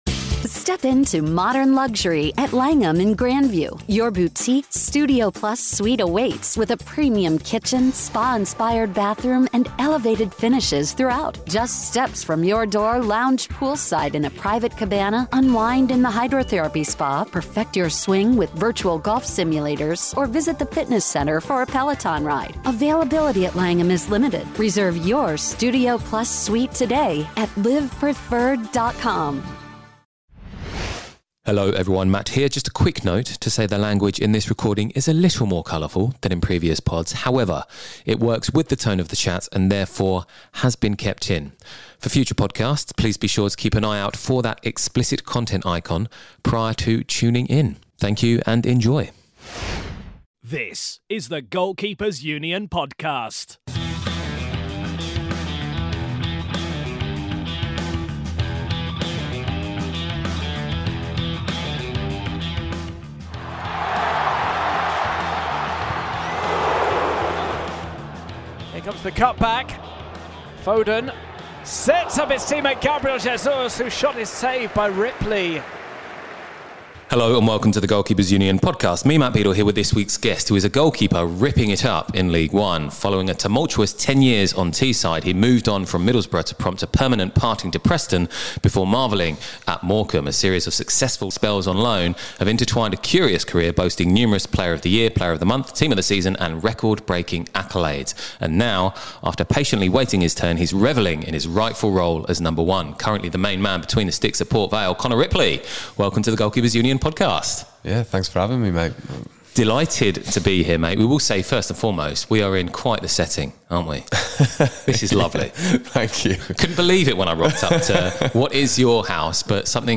NB: Explicit language is used in this recording.